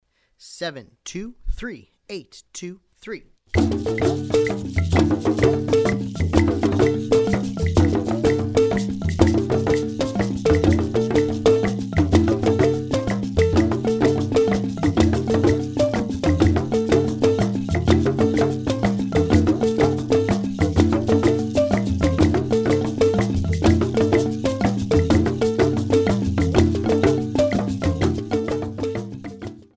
The music combines various percussion instruments,
Medium Triple Meter
(triplet feel)
Medium Triple Meter - 125 triplet